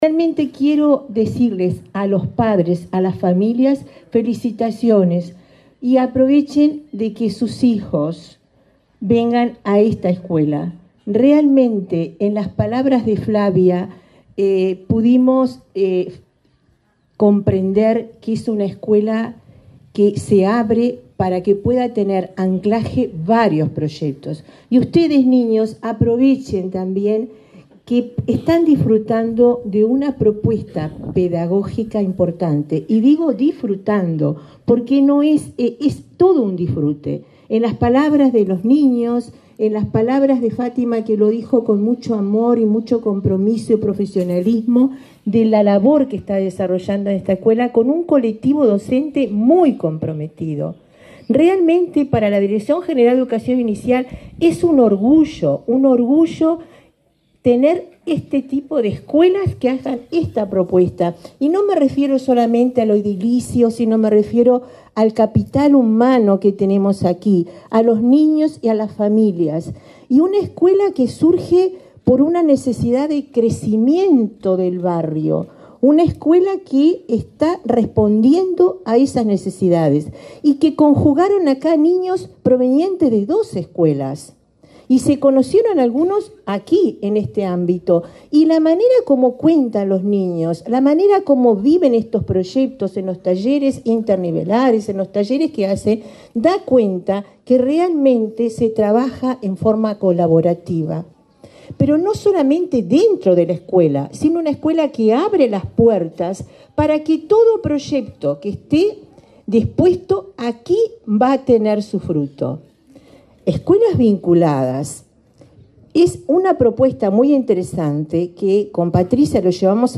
Palabras de la directora general de Educación Inicial y Primaria de ANEP, Olga de las Heras
Palabras de la directora general de Educación Inicial y Primaria de ANEP, Olga de las Heras 03/01/2023 Compartir Facebook X Copiar enlace WhatsApp LinkedIn La directora general de Educación Inicial y Primaria de la Administración Nacional de Educación Pública (ANEP), Olga de las Heras, participó en la inauguración de la escuela n.º 404, de tiempo completo, en el barrio Vista Linda, de Montevideo.